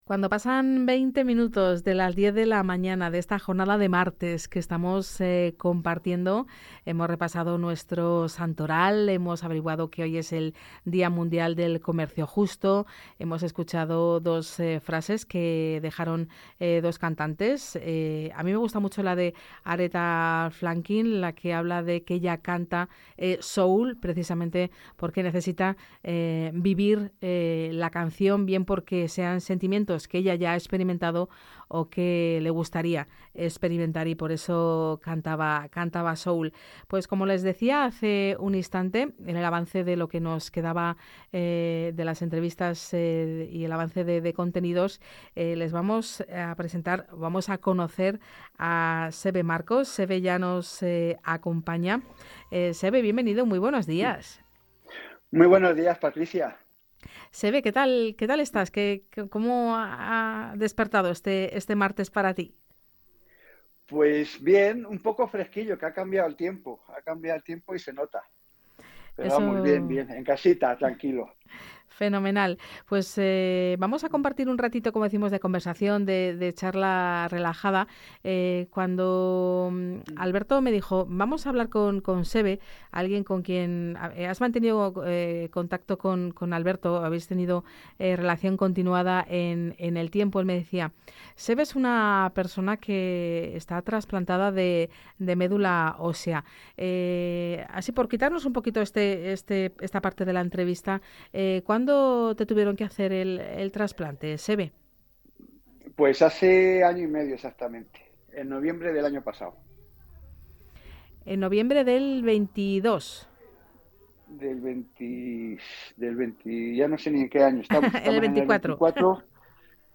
Entrevista en Vive La Radio. Proyecto Solidario " Paseando Por La Vida"
Entrevista Presentación Proyecto Solidario.